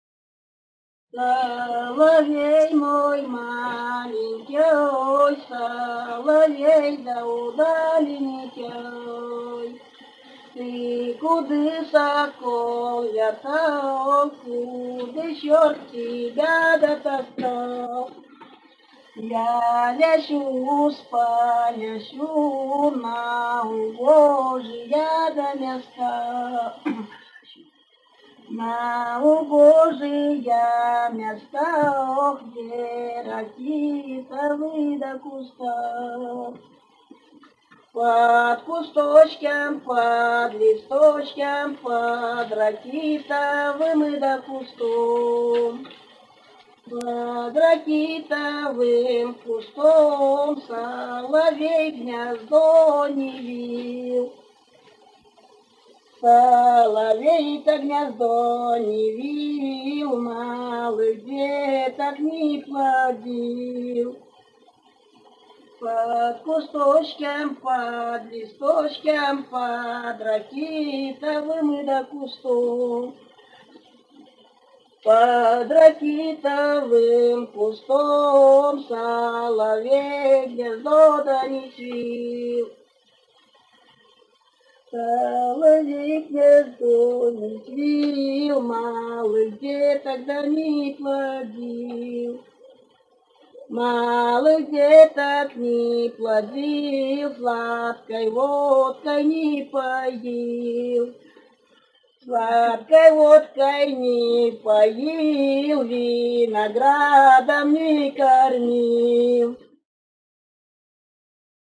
Русские песни Алтайского Беловодья «Соловей мой маленькёй», хороводная (лужошная).
с. Язовая Катон-Карагайского р-на Восточно-Казахстанской обл.